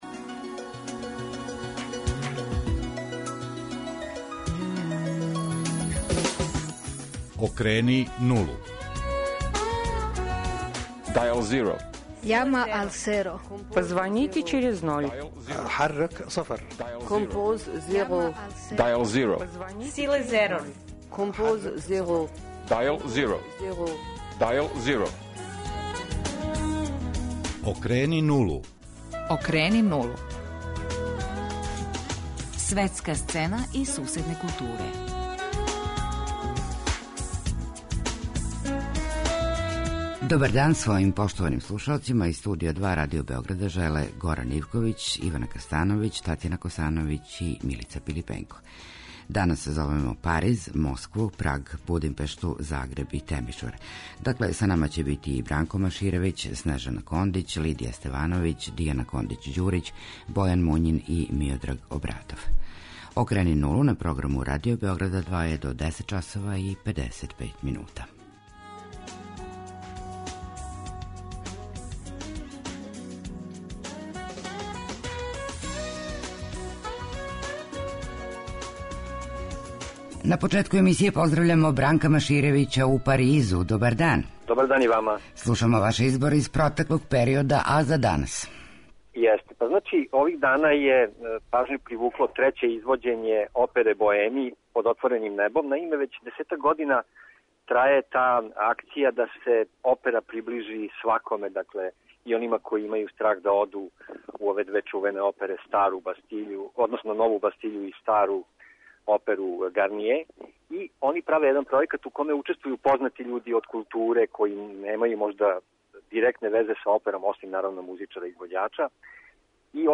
Београдски део екипе емисије Окрени нулу позваће дописнике Радио Београда 2 из Париза (слика на насловној страни), Москве, Прага, Будимпеште, Загреба и Темишвара.